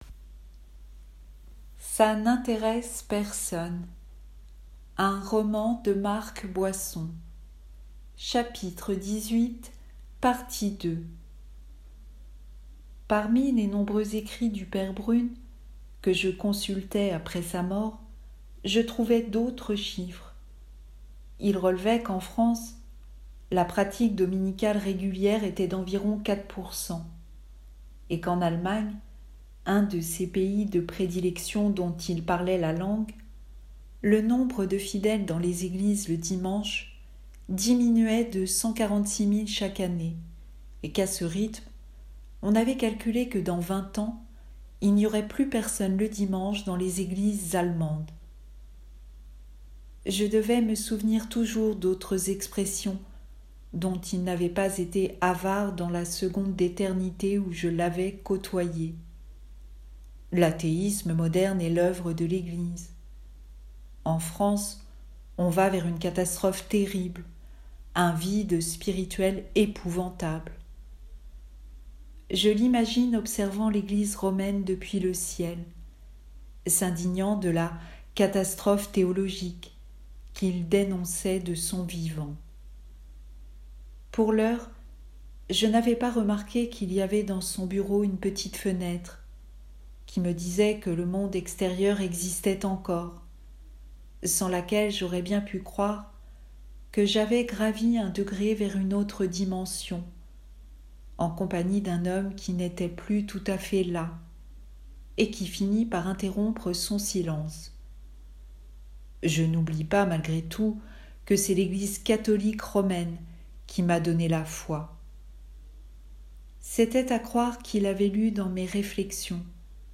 Un extrait de Ça n’intéresse personne.